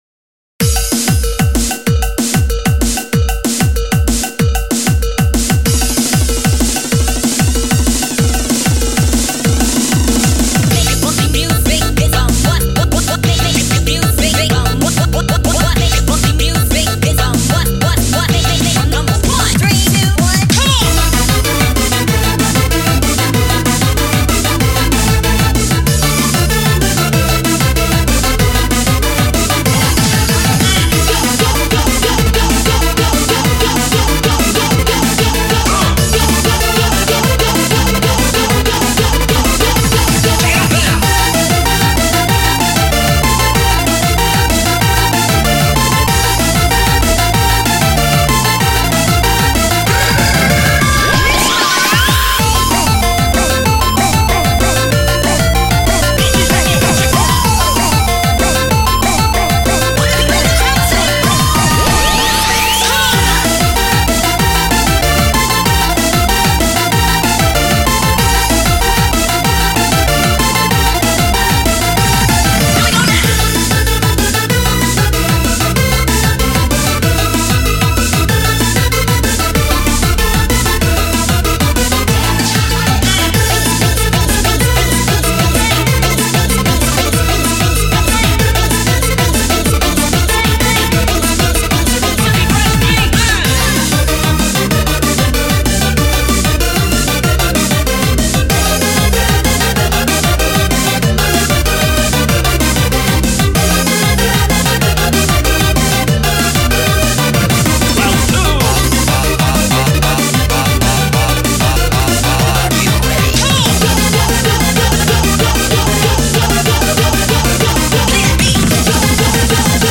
BPM190
Audio QualityPerfect (High Quality)
Comments[Russian Megamix Funkot]